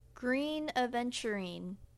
Pronunciation
Green Aventurine, ə venchə rēn′, -rin